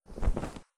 net_picked_up.mp3